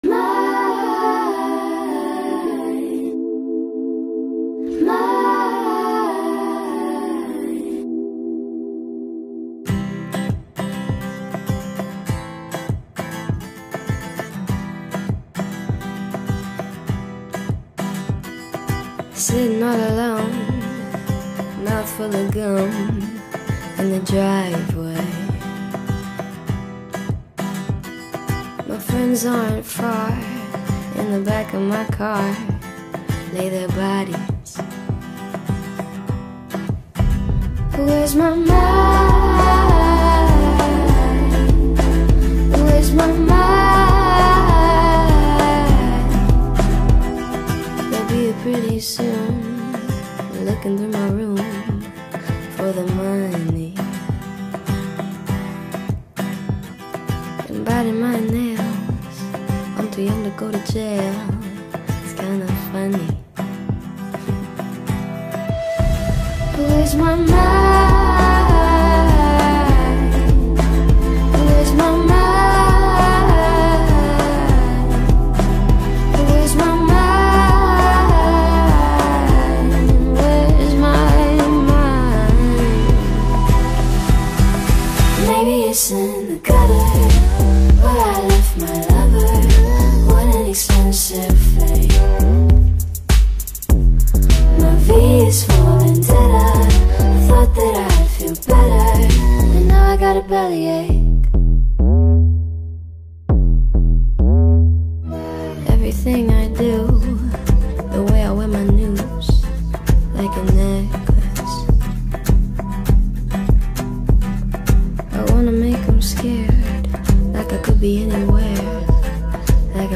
BPM100
Audio QualityCut From Video